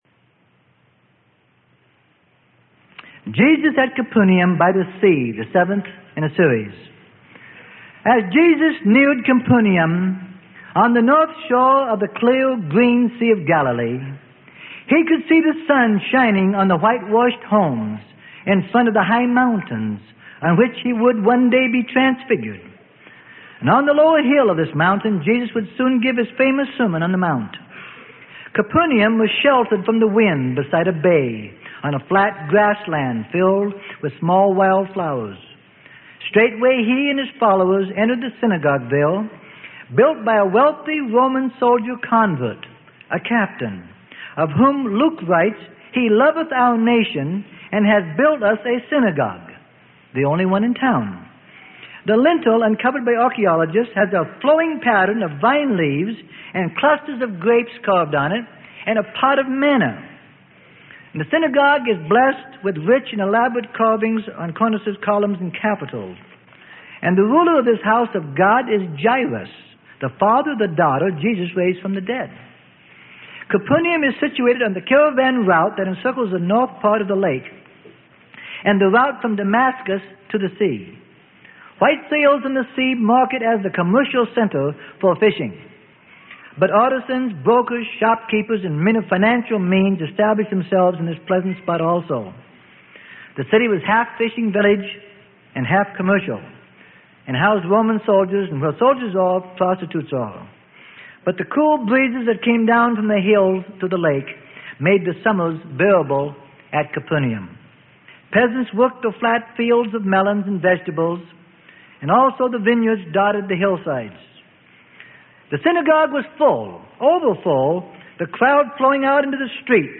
Sermon: The Life Of Jesus - Part 07 Of 33.